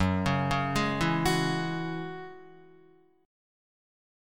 F#7sus2 chord